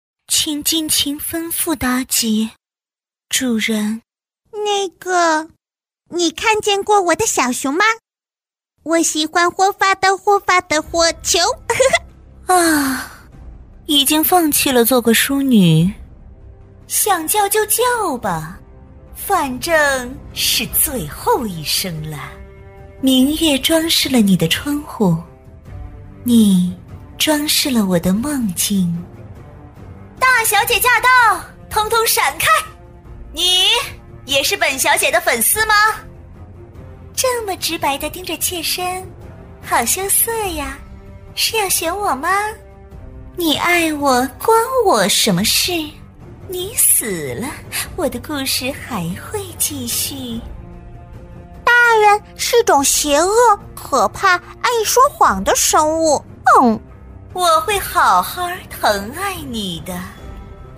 女101-游戏人物【多个人物】
女101-游戏人物【多个人物】.mp3